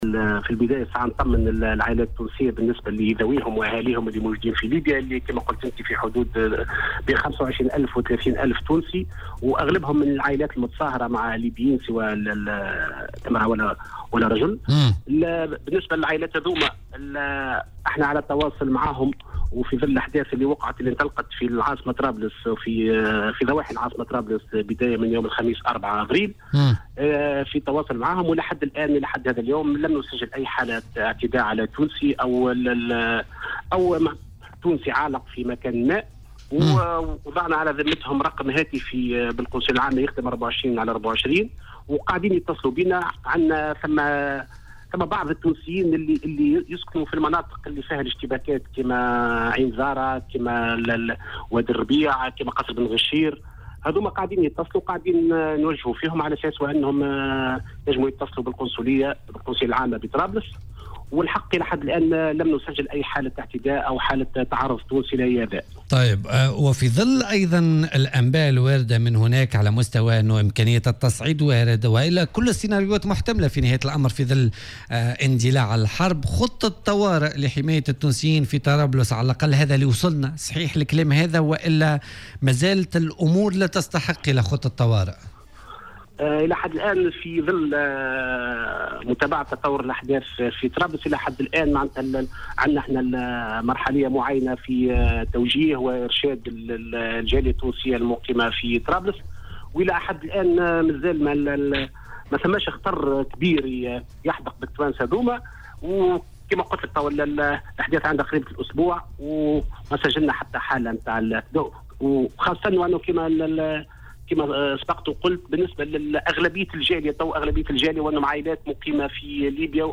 وأوضح في مداخلة له اليوم في برنامج "بوليتيكا" على "الجوهرة أف أم" أنه لم يُسجّل أي حالة اعتداء على التونسيين في ليبيا وأن القنصلية وضعت رقما هاتفيا لتوجيه القاطنين بمناطق الاشتباكات في كل من "عين زارة" و"وادي الربيع" وغيرها... وأضاف أيضا أنه على التونسيين الراغبين في العودة إلى تونس في صورة تطور الأحداث في ليبيا التوجه عبر مطار معيتيقة أو مصراتة أو اعتماد طريق طرابلس رأس جدير.